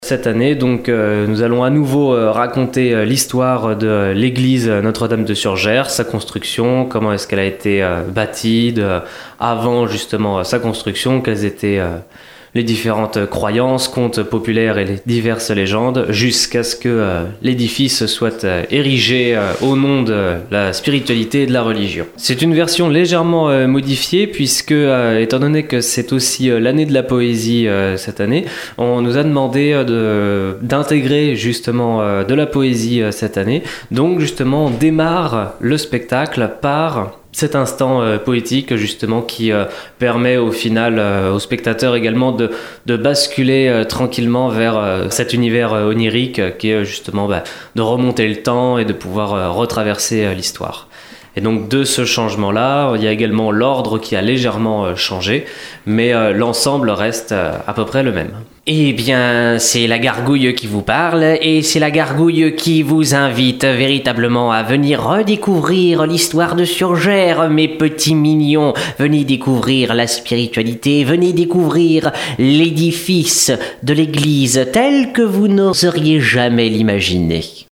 L'INFO LOCALE EN CONTINU